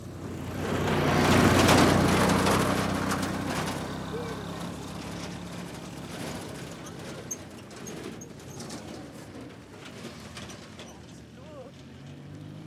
加沙 " 汽车真正的通行证，快速响亮的颠簸的泥土
描述：汽车真正传递快速rattly颠簸dirt.wav
标签： 颠簸 传球 rattly 快速 自动 污垢
声道立体声